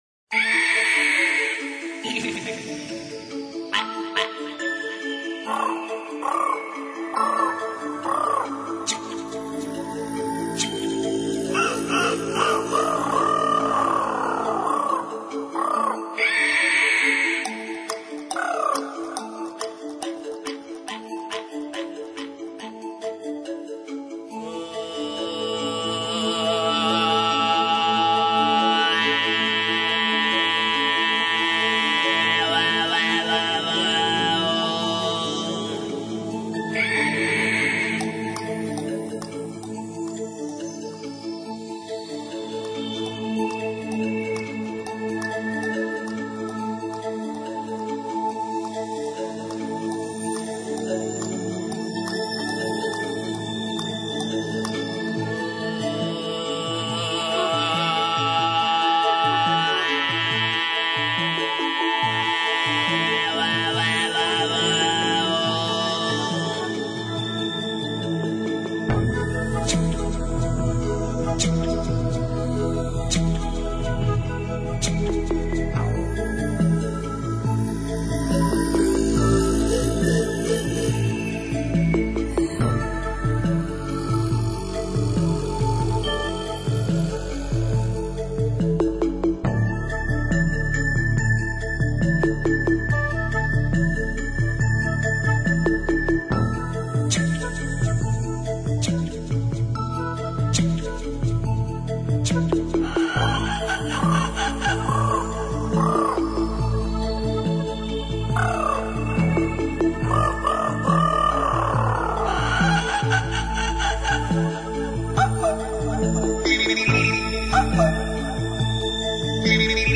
горловое пение (открыта)